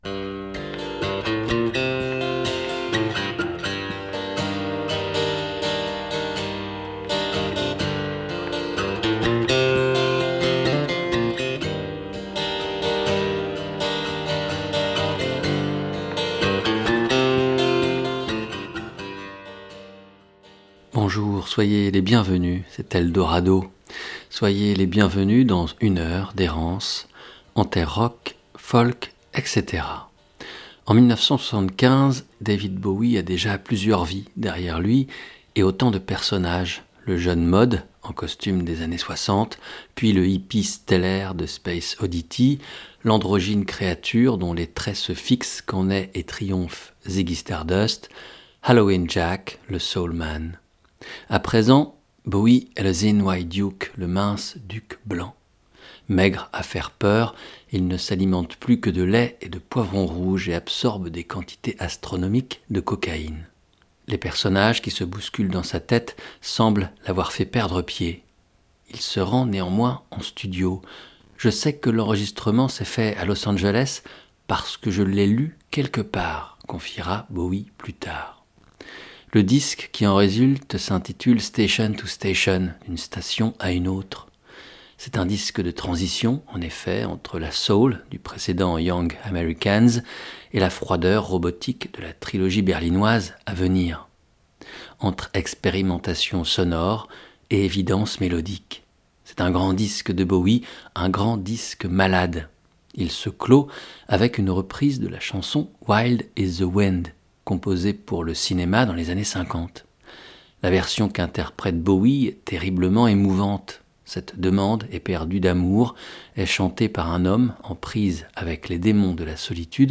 D'un artiste à un autre, on retrace les parcours, les rencontres de chacun pour finir par comprendre comment les choses ont évolué. Chaque émission est un road trip prétexte à la (re)découverte ou la contemplation de ceux qui ont écrit l'histoire de ce courant...